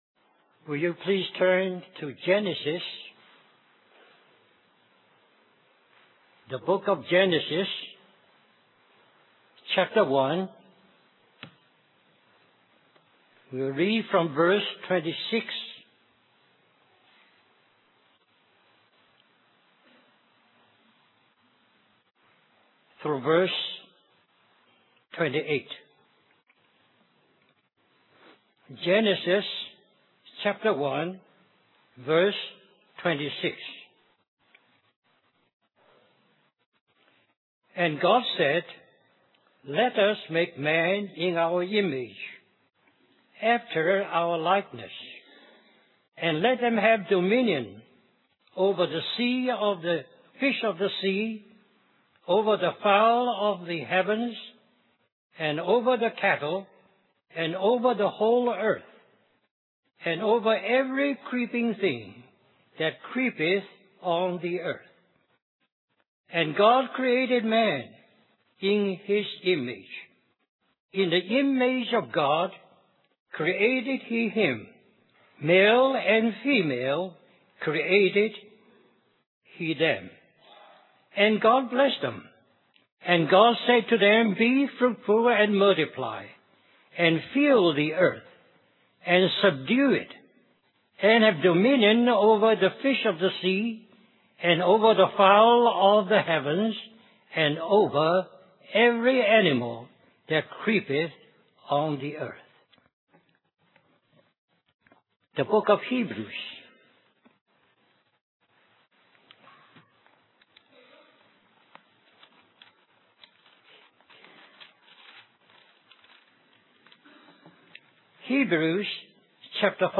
2005 Christian Family Conference Stream or download mp3 Summary Why does God give us responsibility? What does He have to accomplish through this gift?